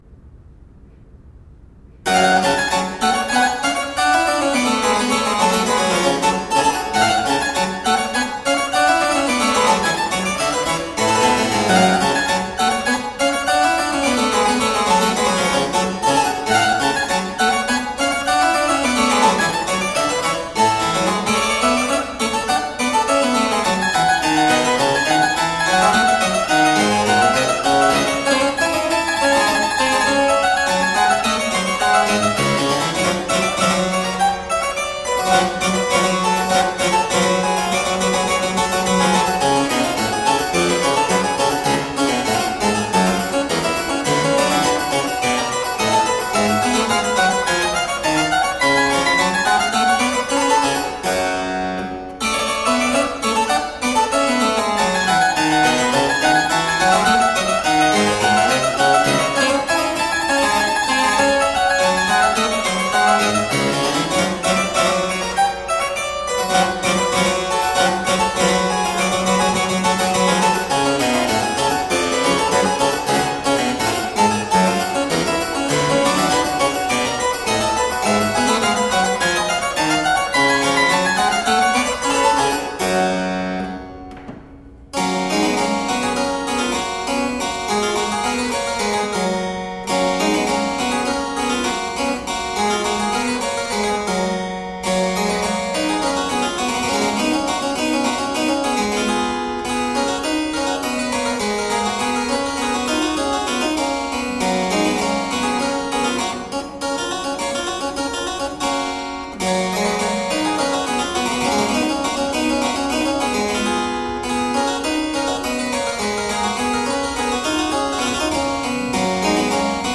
Harpsichord and fortepiano classics.